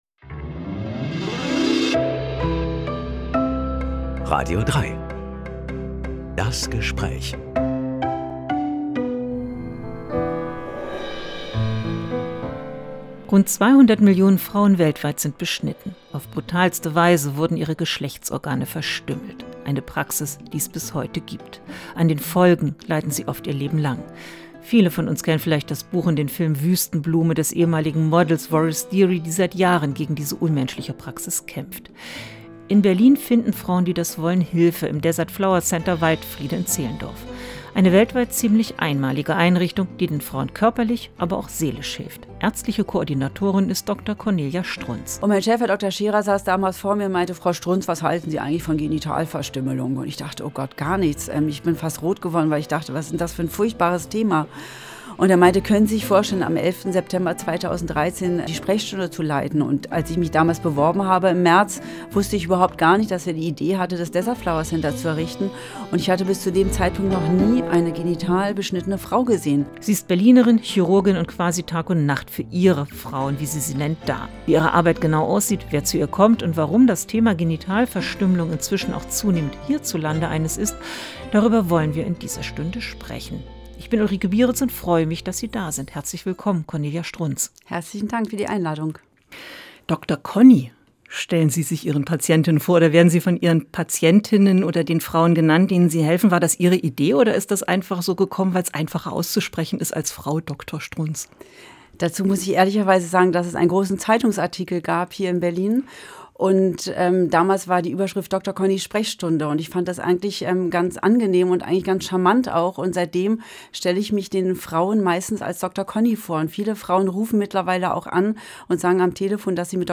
Reden mit einem Menschen.